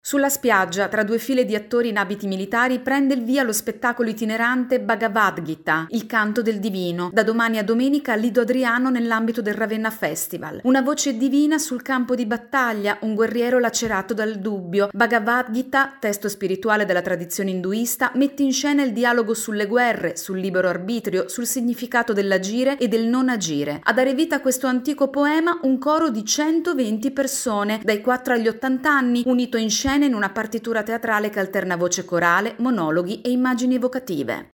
Il senso della guerra – a Ravenna Festival, in scena il Canto del Divino in un’esperienza di teatro di comunità. Il servizio